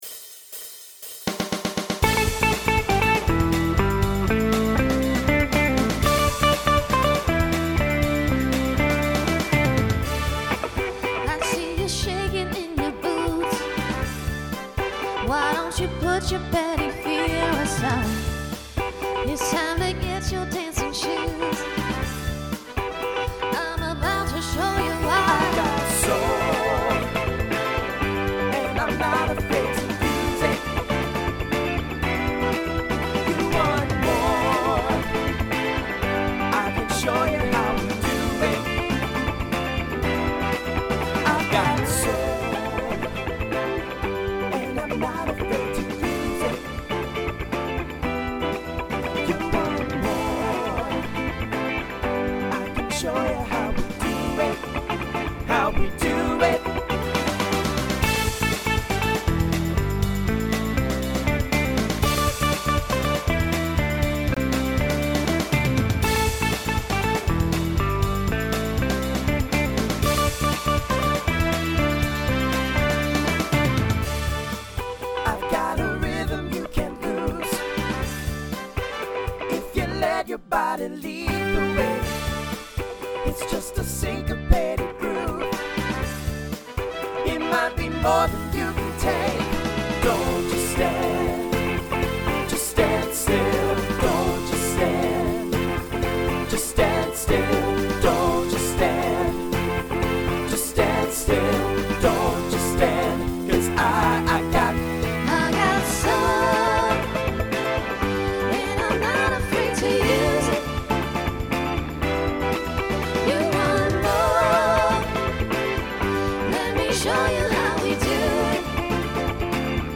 guys/girls feature
Voicing Mixed Instrumental combo Genre Pop/Dance